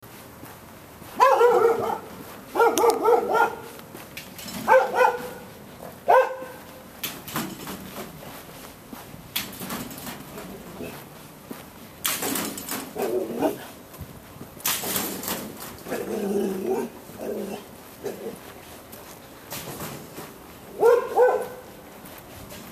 A Dog Barks In The Street Sound Effect Download: Instant Soundboard Button